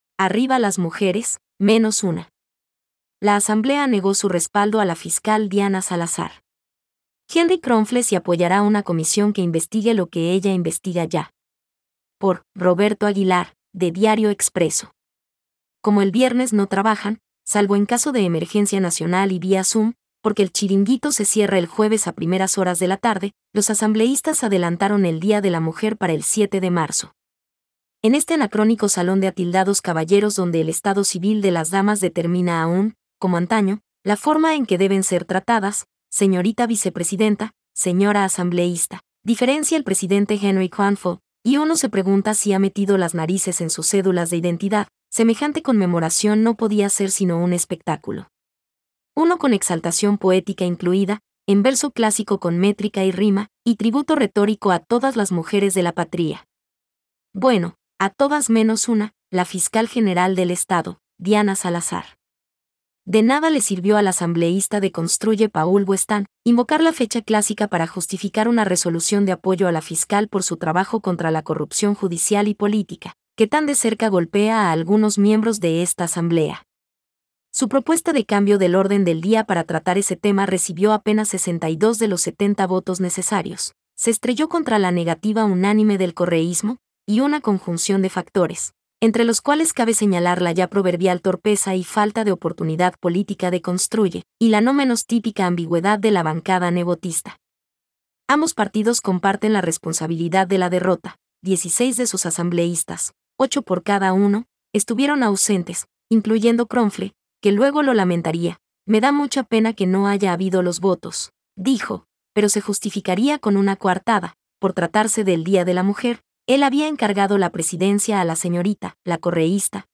Información hablada